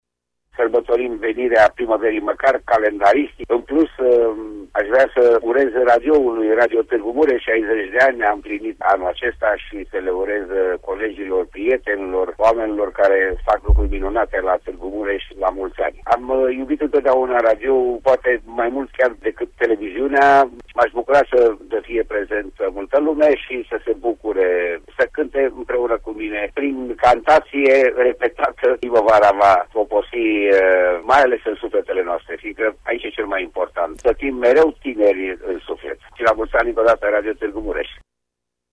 Ducu Bertzi, în exclusivitate pentru Radio Tg.Mureș: